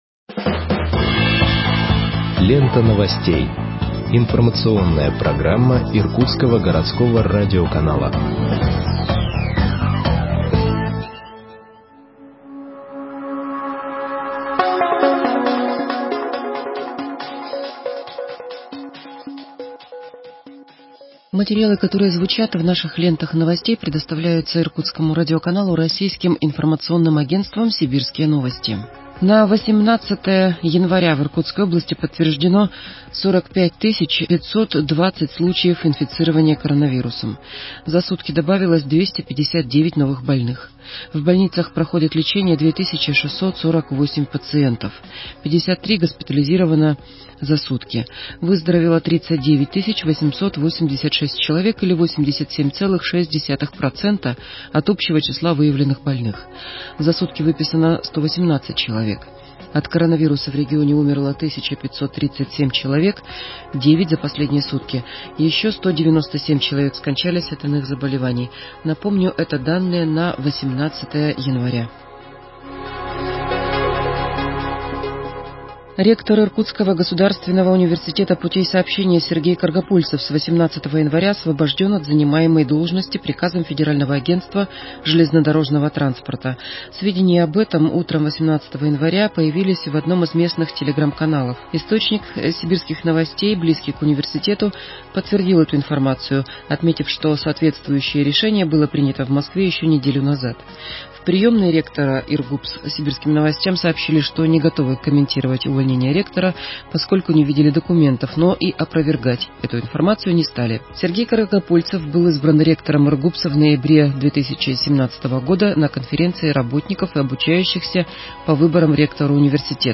Выпуск новостей в подкастах газеты Иркутск от 19.01.2021 № 2